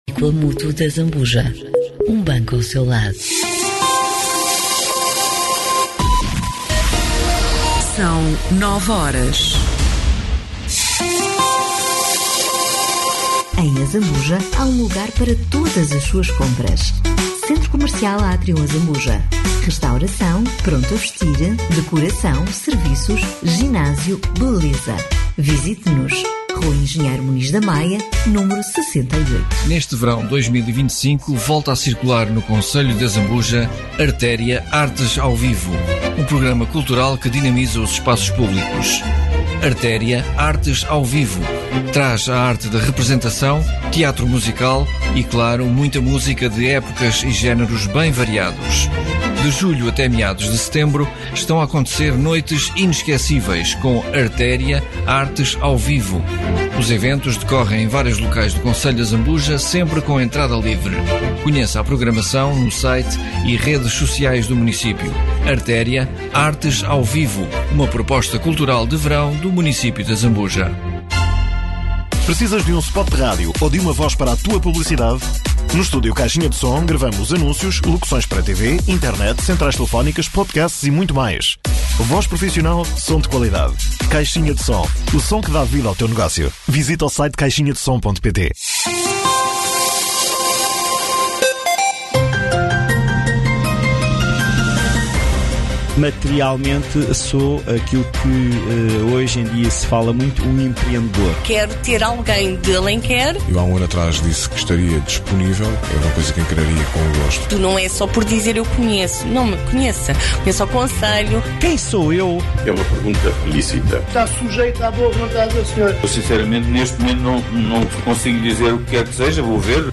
Autárquicas 2025 - Azambuja - Entrevista